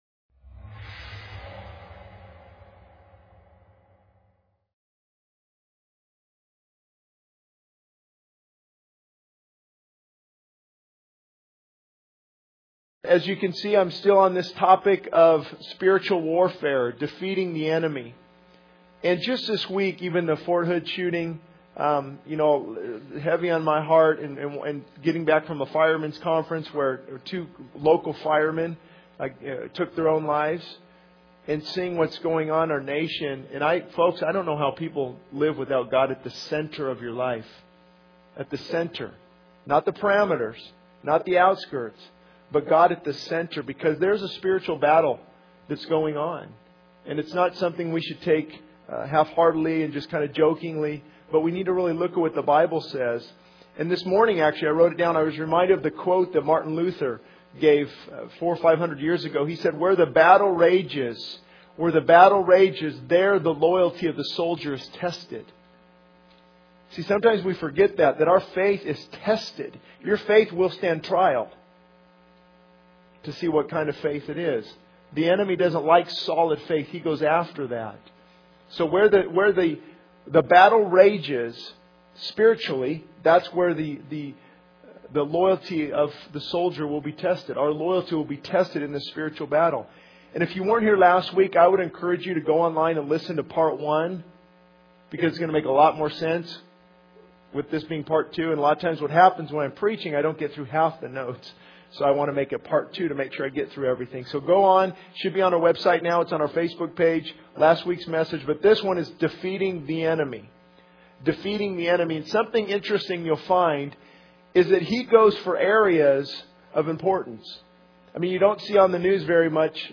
This sermon emphasizes the importance of spiritual warfare and defeating the enemy by focusing on the need to have God at the center of our lives. It highlights the battle against spiritual forces and the loyalty tested in this battle.